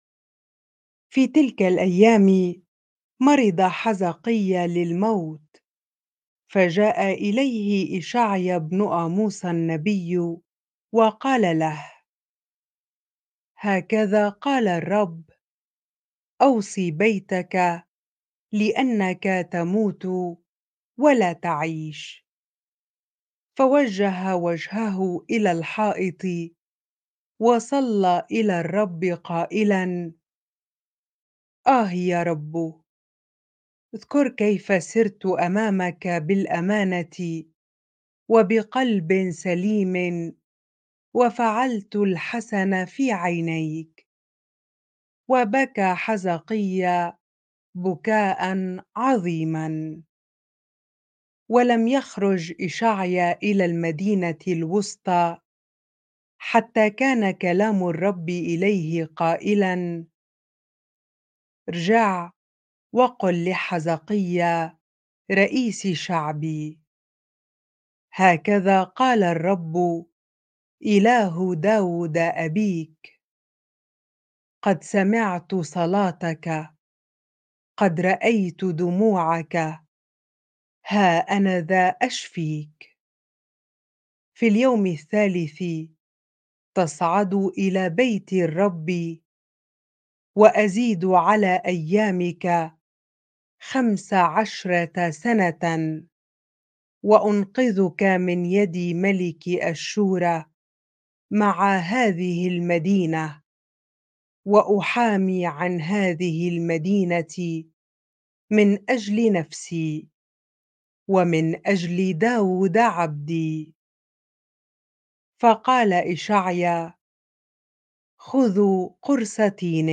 bible-reading-2 Kings 20 ar